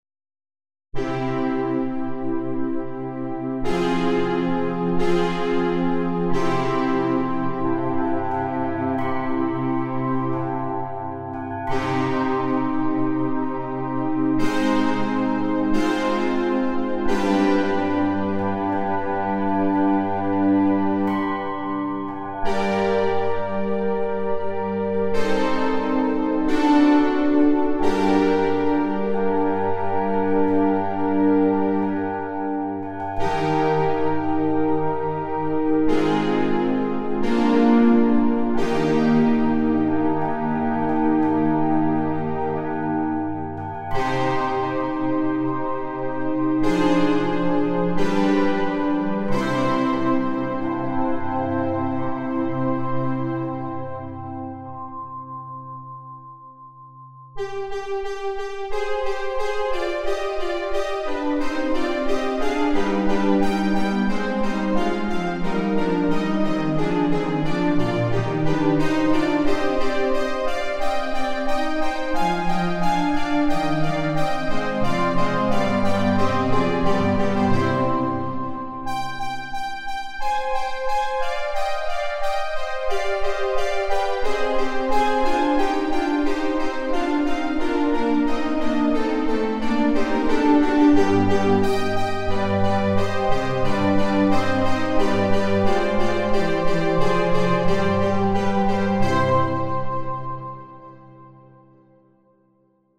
Excerpt from "Music for the Funeral of Queen Mary" by Henry Purcell, created on GarageBand software (synthesizer). Actually, just a basic test I did many years ago as a complete beginner...